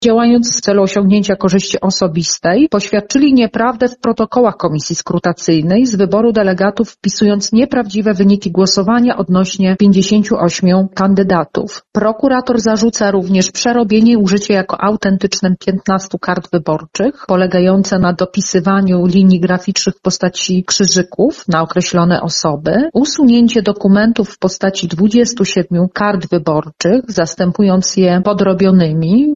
mówi rzecznik prasowy Sądu Okręgowego w Lublinie, sędzia Marta Śmiech.